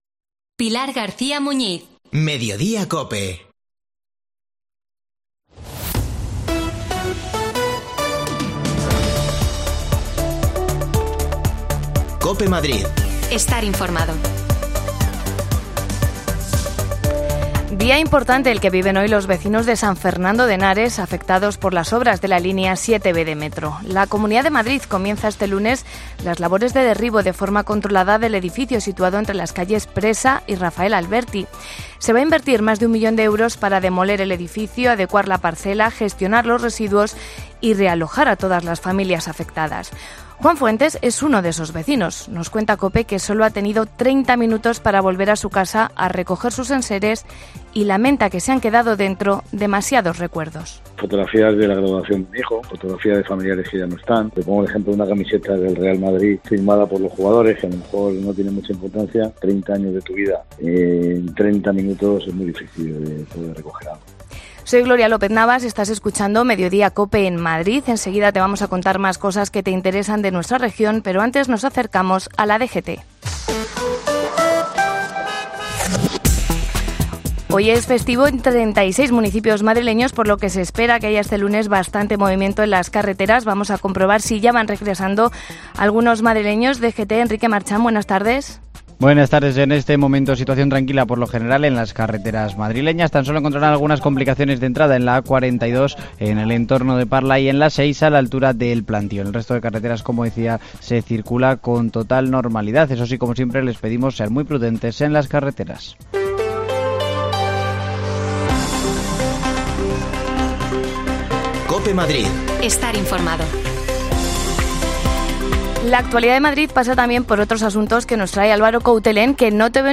AUDIO: Comienzan las labores de derribo del edificio de San Fernando de Henares afectado por las obras de la línea 7B de Metro. Hablamos con los vecinos.